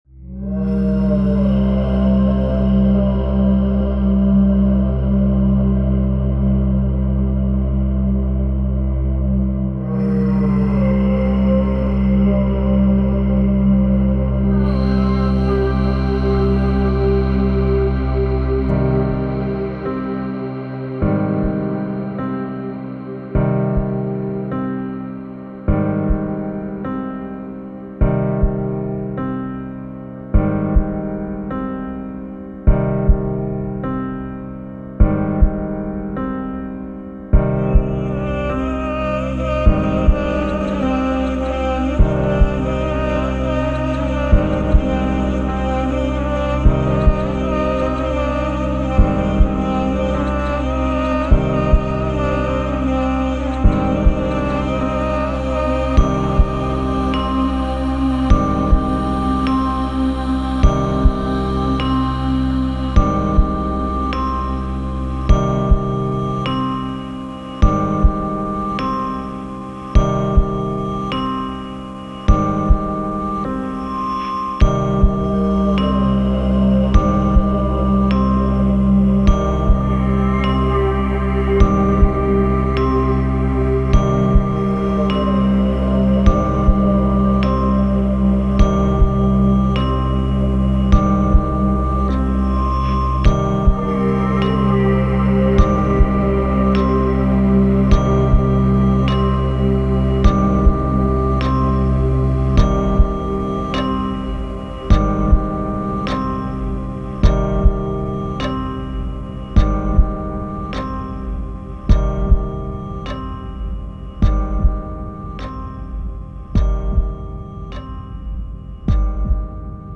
心臓の鼓動を中心に据えて、命が尽きる時に聴こえそうな音色を詰め込んでいる。